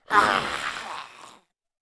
Index of /App/sound/monster/orc_magician
dead_1.wav